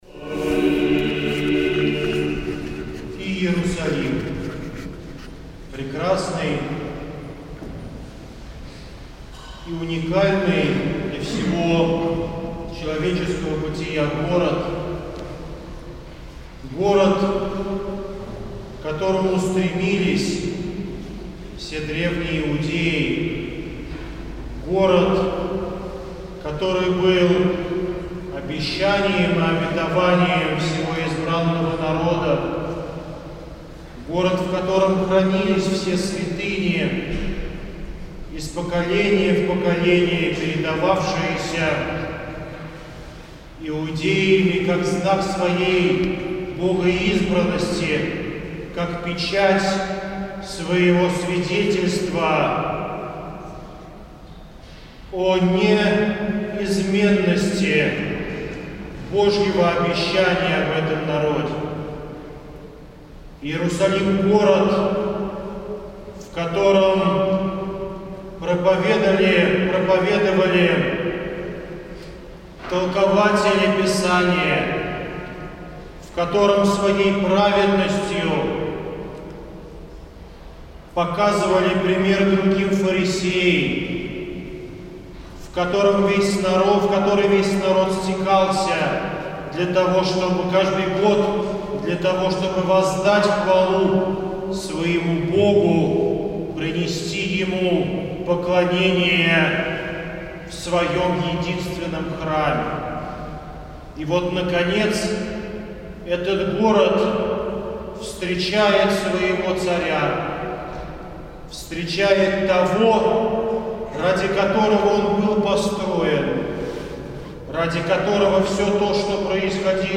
Вход Господень в Иерусалим: проповеди
Всенощное бдение